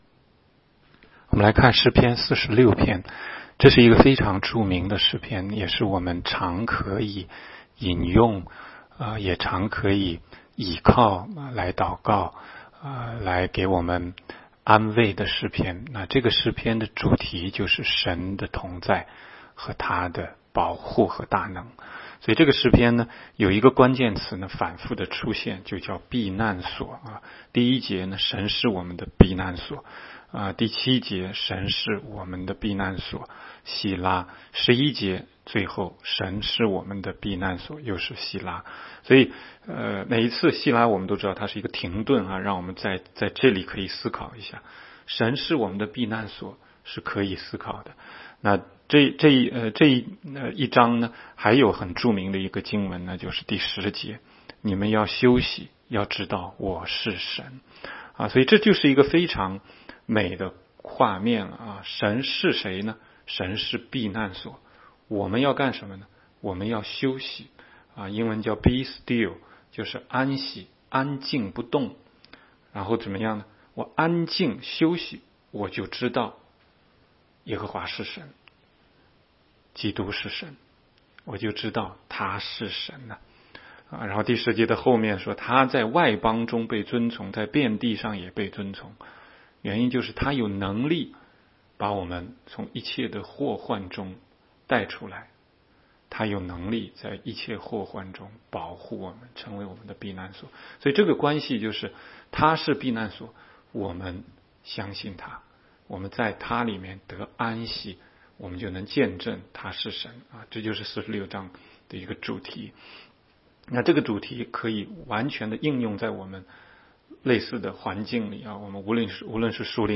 16街讲道录音 - 每日读经-《诗篇》46章